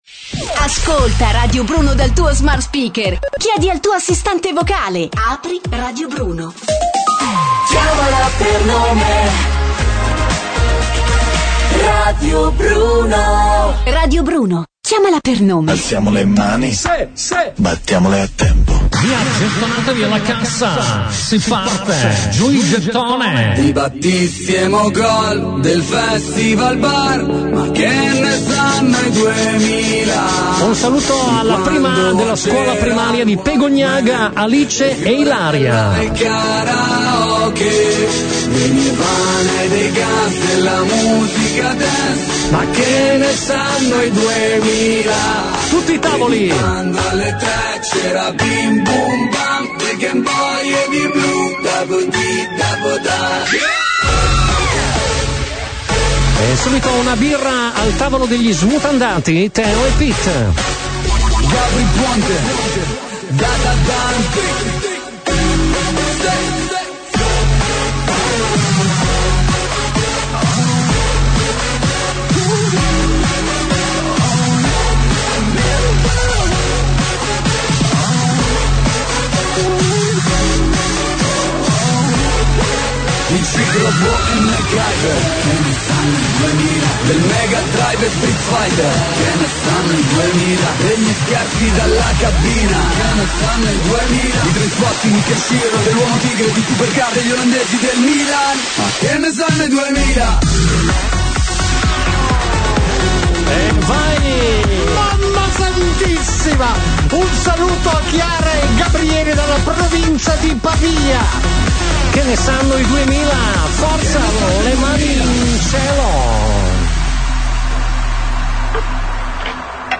Torna il meglio del peggio de La Strana Coppia! Oggi vi aspetta la CuccuWeek: gettonatevi con le canzoni più tamarre mixate
Non perdetevi i commenti degli ascoltatori